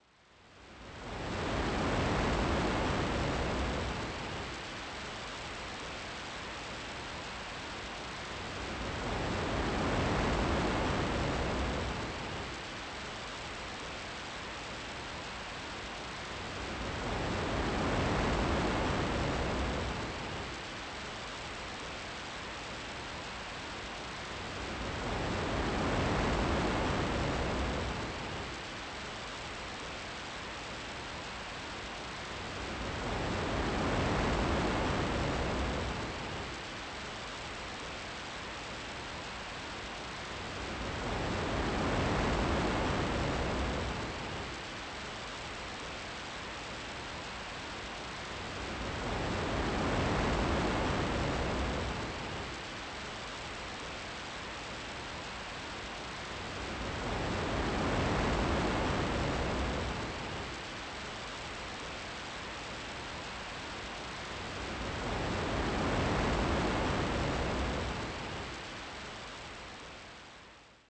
Type BGM
Speed 50%